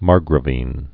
(märgrə-vēn)